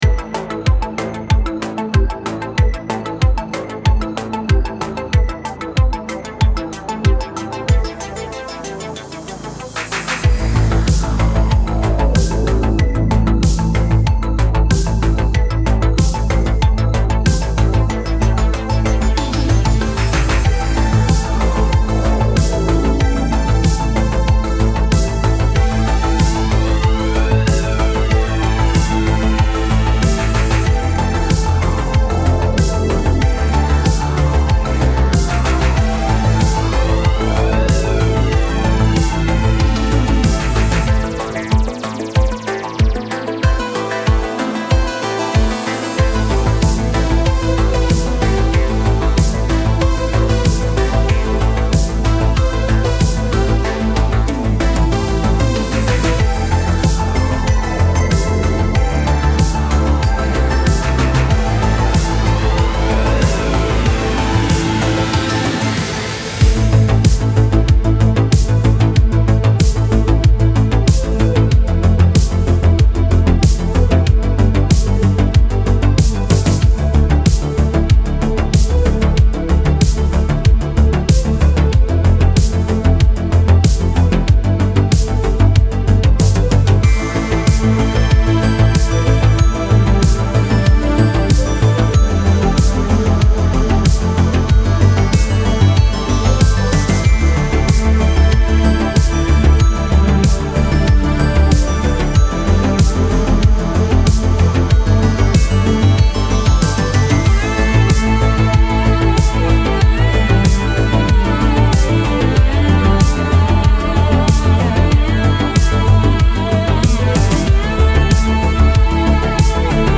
此包专注于重现Roland Juno 106 / Jupiter 8 / JX8p，Minimoog，Korg Poly 6等模拟经典的灵魂，以及DSI OB6和Model D等现代类似合成器。除了这些经典声音之外，我们还创建了许多现代郁郁葱葱的垫子，柏木贝司和史诗般的主音，涵盖了合成器制作人所需的所有声音。